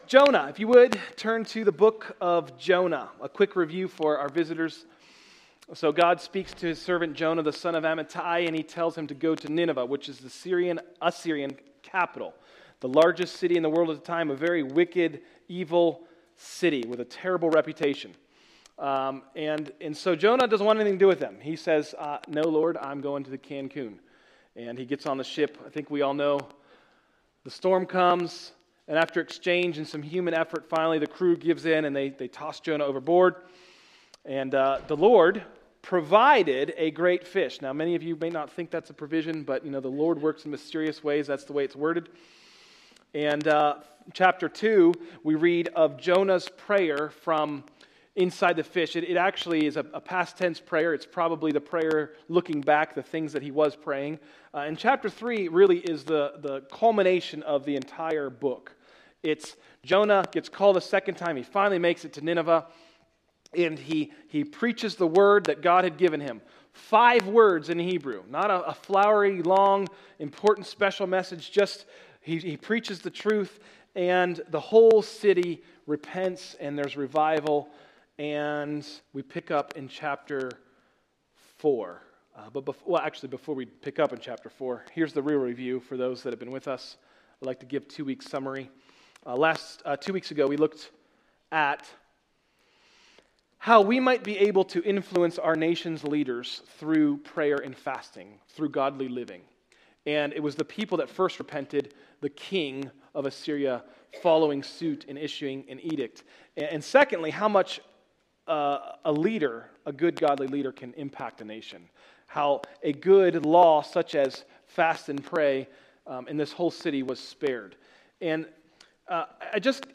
In this sermon we look at chapter four how God loves, forgives and saves. How can we be more like Him?